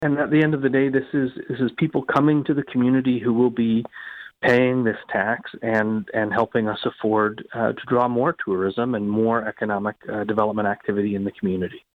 Mayor of Brighton Brian Ostrander.